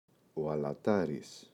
αλατάρης, ο [alaꞋtaris]